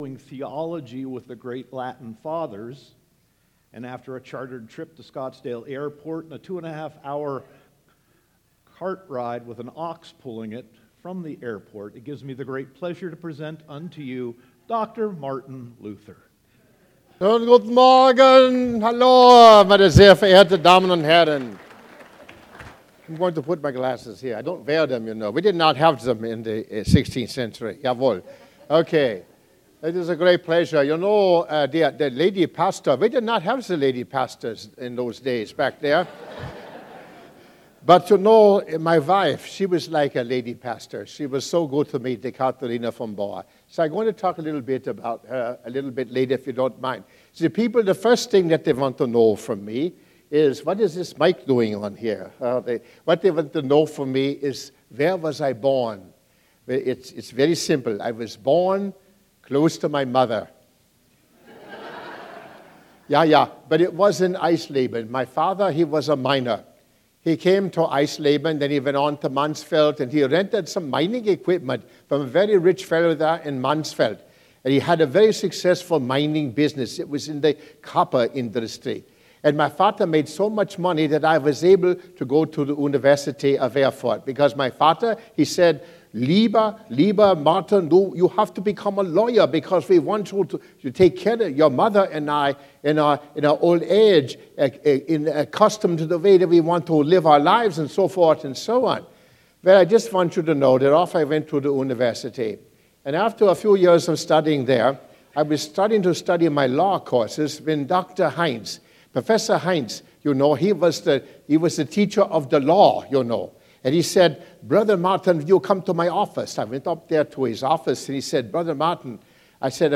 Sermon 10.29.2017